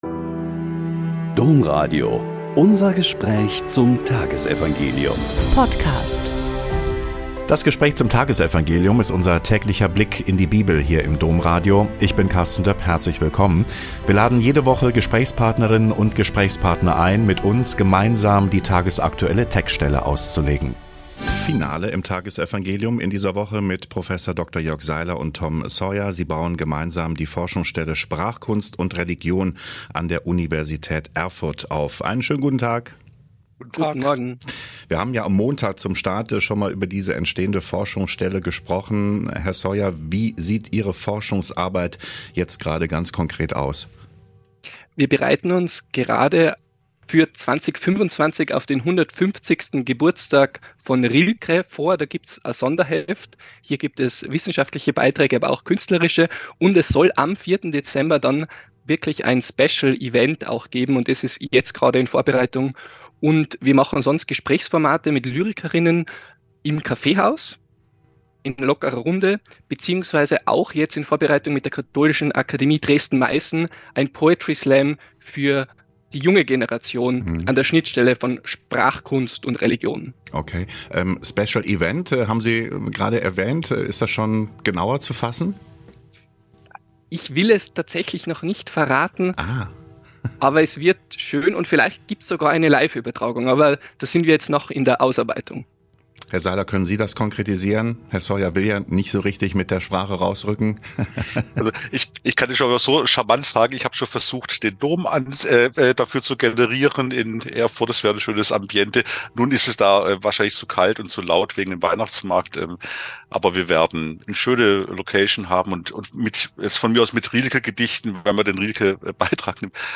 Mk 11,27-33 - Gespräch